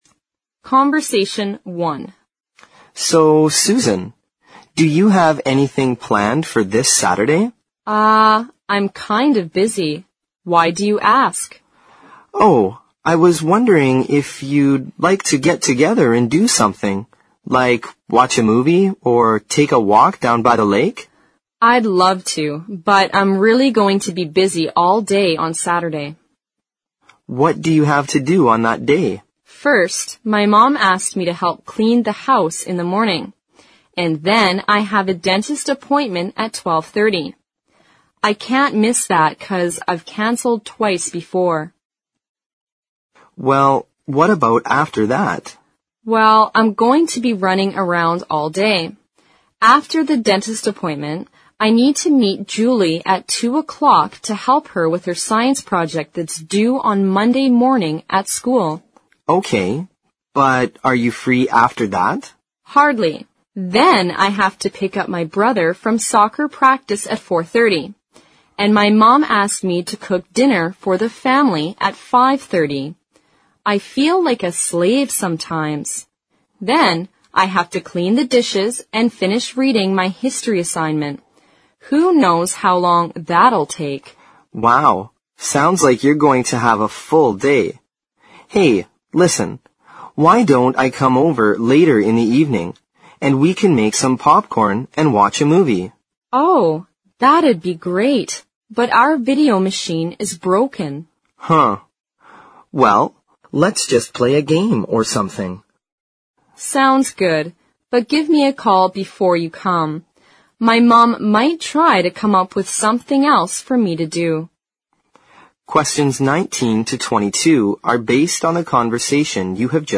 Conversation One